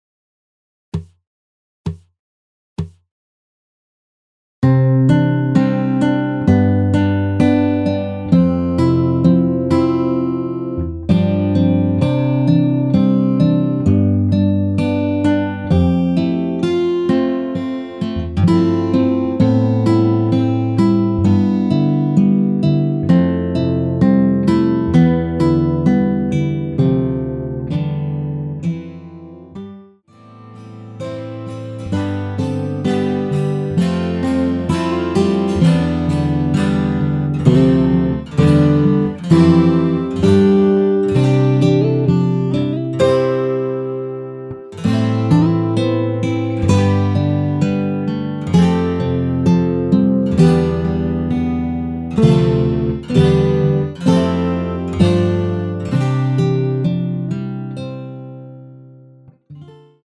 전주없이 시작 하는 곡이라 카운트 넣어 놓았습니다.(미리듣기 참조)
원키에서(+9)올린 (1절+후렴)으로 진행되는 MR입니다.
앞부분30초, 뒷부분30초씩 편집해서 올려 드리고 있습니다.